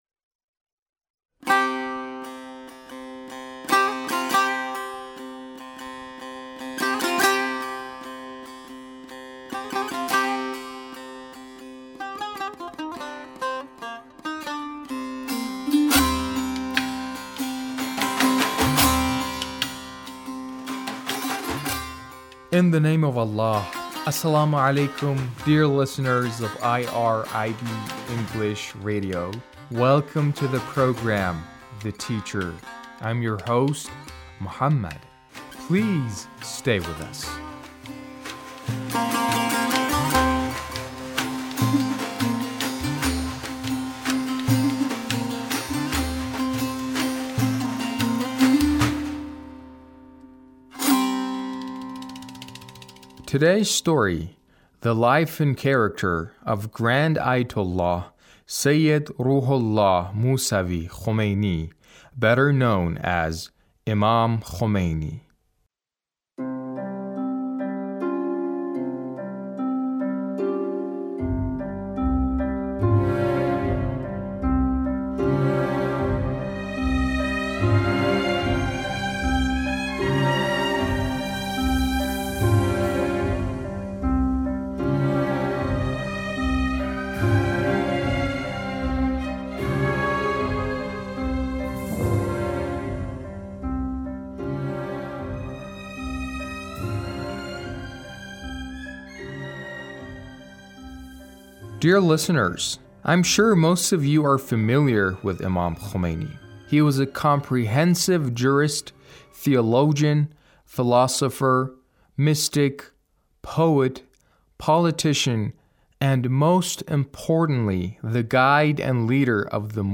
A radio documentary on the life of Imam Khomeini